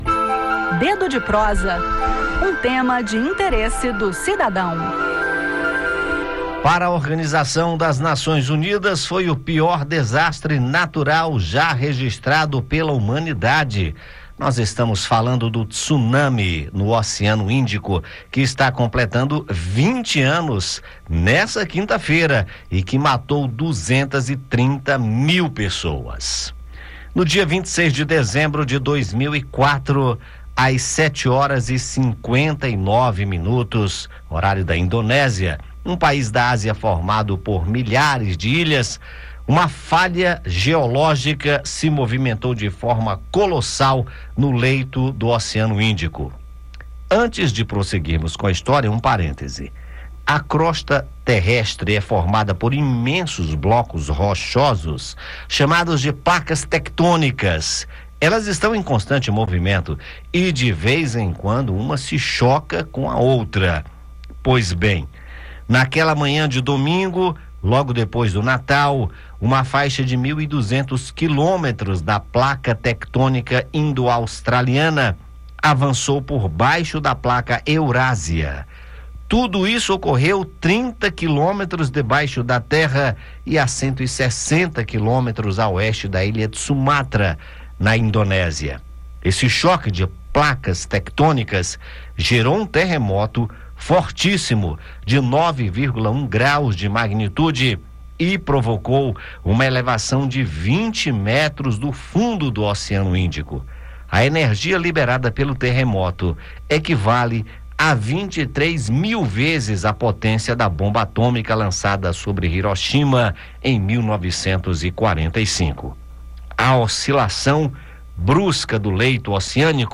Ouça no bate-papo como ocorre esse tipo de desastre que atingiu principalmente a Indonésia e a Tailândia, com ondas gigantes que chegaram a 30 metros de altura.